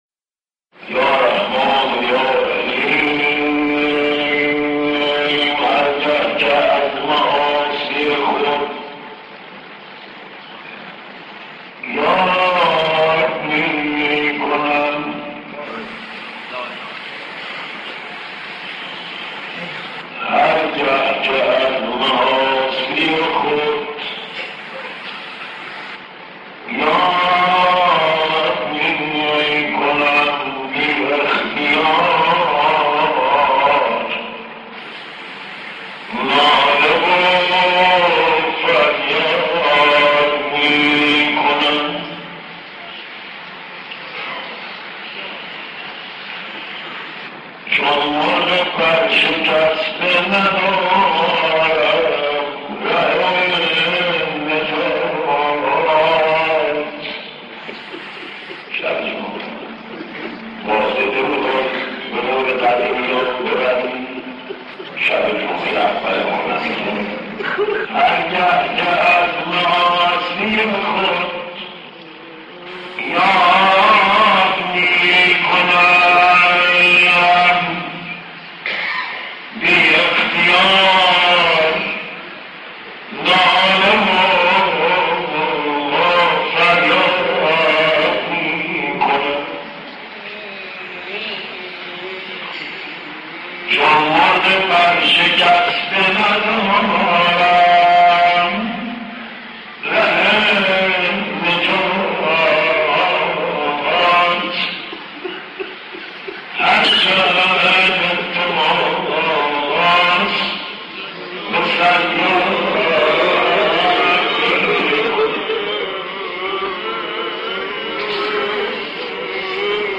مداحی
در اولین شب جمعه ماه مبارک رمضان